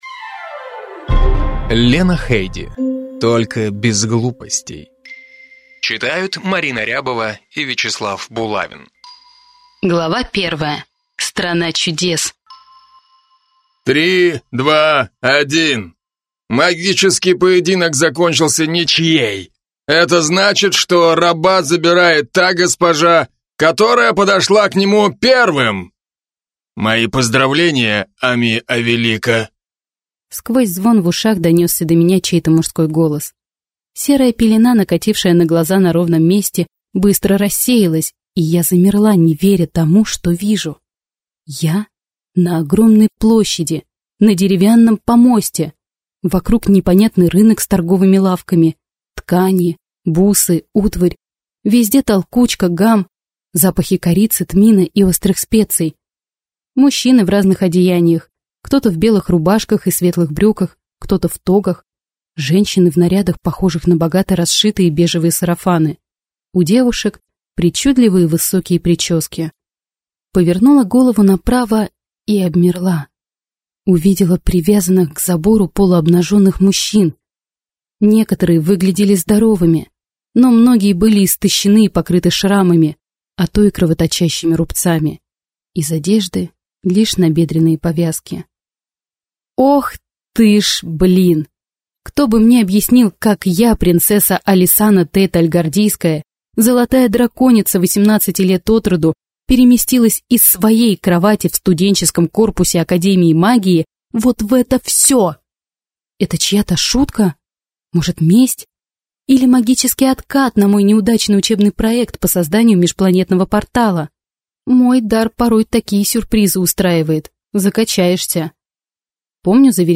Аудиокнига Только без глупостей!